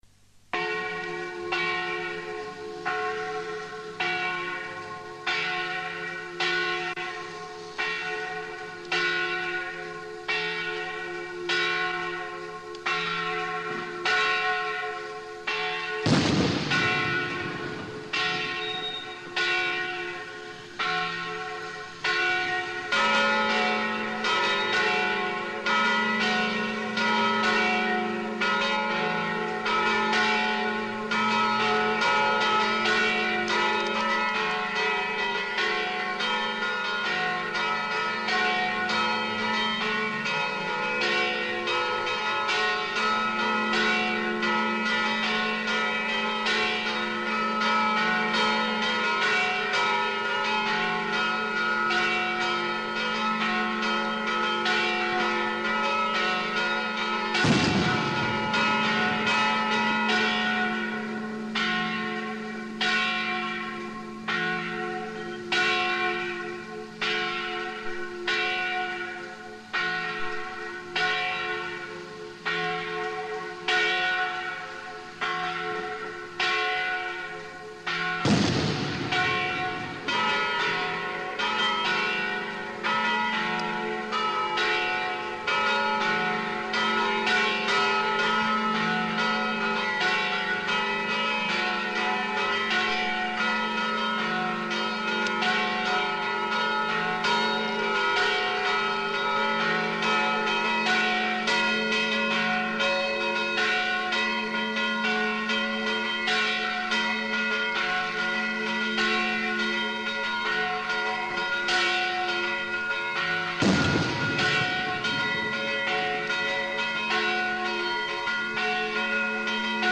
Ovviamente la registrazione è di tipo artigianale ed è realizzata con i mezzi disponibili all'epoca, ma ciò a mio avviso arricchisce ulteriormente il valore di testimonianza di ciò che ascolterete.
I SUONI DELLE CAMPANE DI SAN MICHELE
Festa di San Michele 1980
campane_di_san_michele_2.mp3